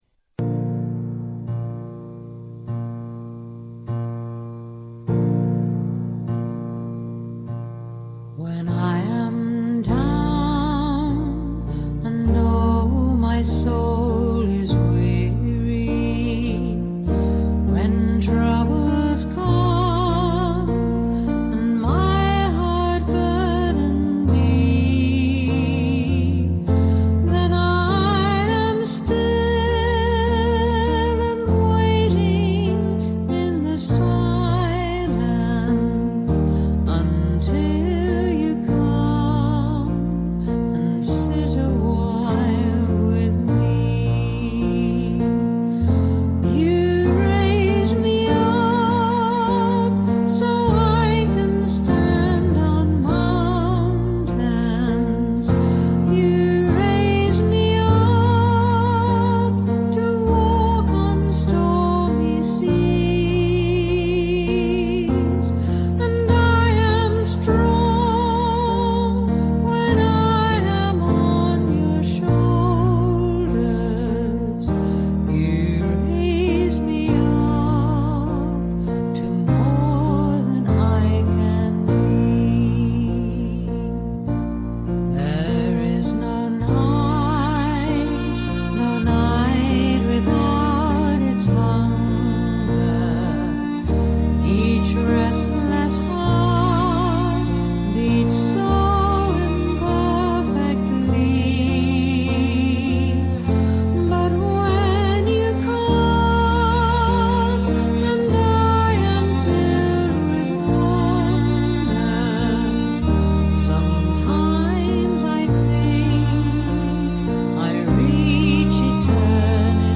violin, octave violin & viola
vocals, keyboards & violin